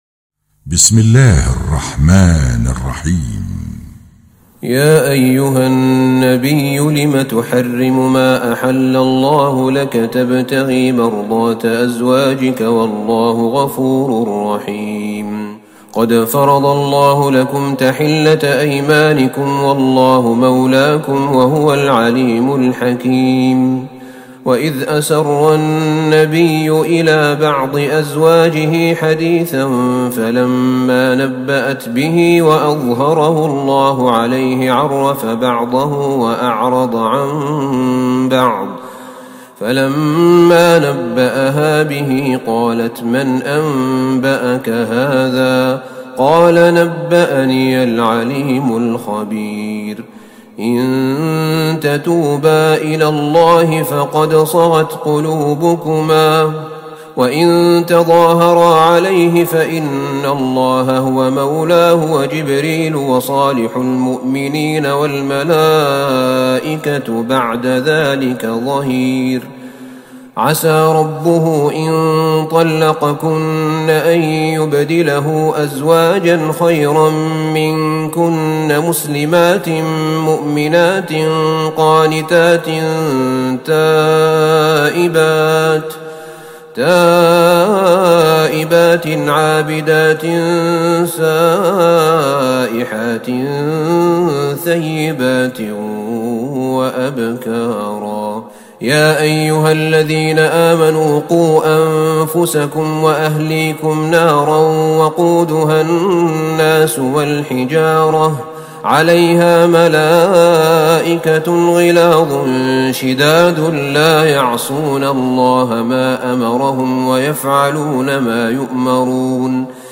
تراويح ٢٩ رمضان ١٤٤١هـ من سورة التحريم إلى الجن > تراويح الحرم النبوي عام 1441 🕌 > التراويح - تلاوات الحرمين